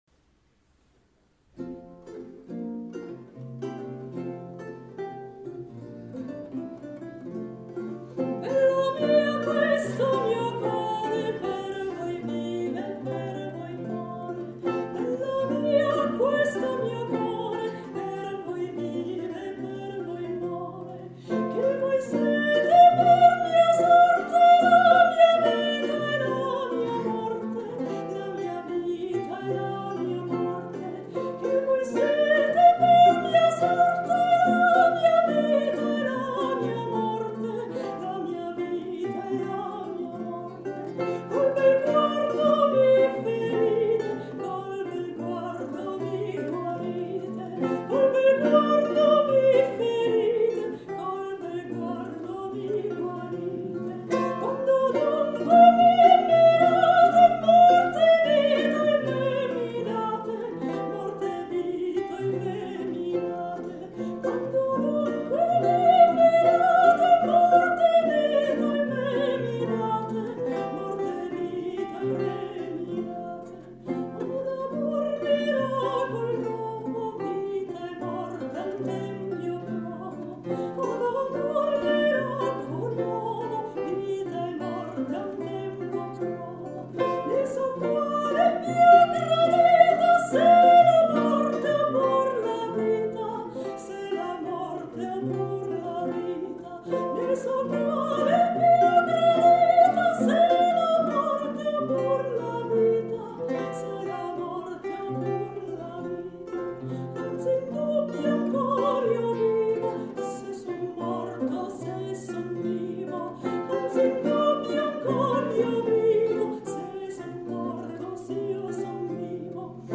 Conserto Vago
Registrazioni dal vivo di alcuni concerti del Festival di Musica Antica di Salerno